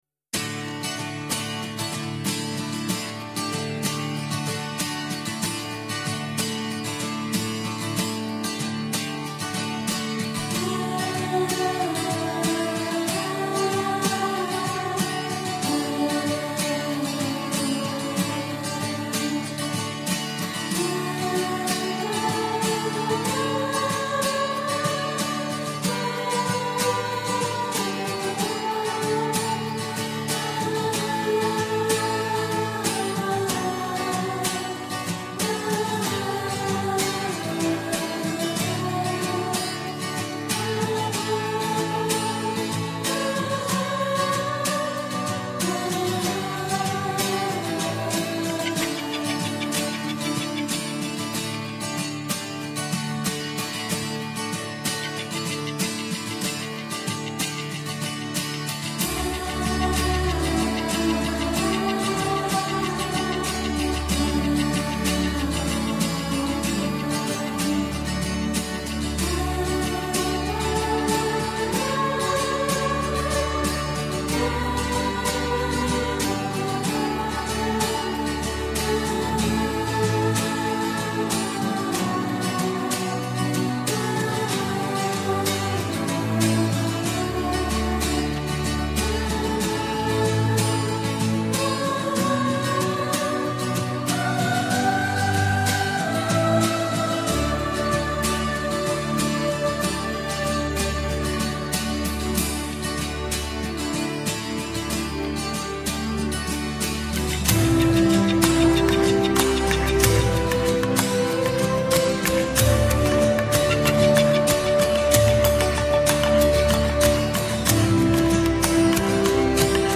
【日本New Age】
更荣膺日本金碟大赏“的”年度纯音乐专集（Instrumental Album）“第二名。
整张专辑如微风轻舞浪花般，舒缓都市人的重重压力和抑郁的情绪， 给人
一种清澄透明、波光旖旎的水漾感受，是日本乃至全世界乐迷所珍爱的经典大碟。